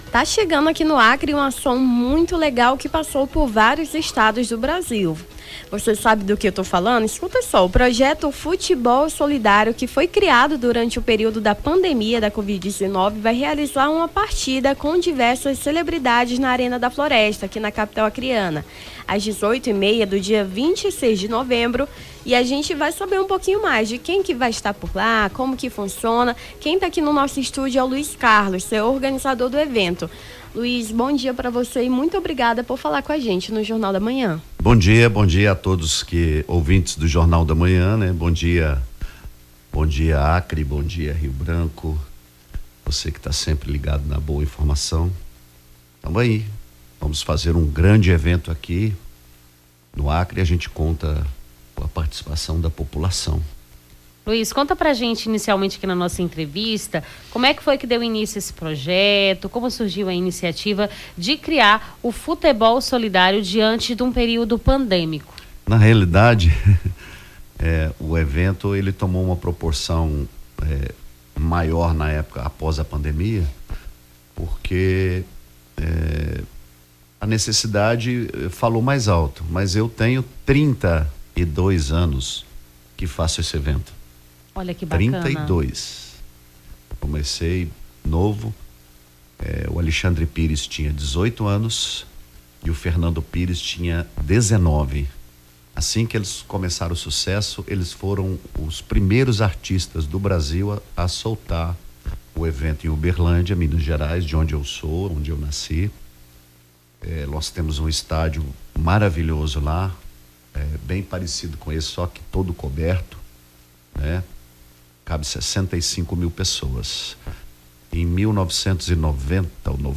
Nome do Artista - CENSURA - ENTREVISTA JOGO SOLIDÁRIO (22-11-24).mp3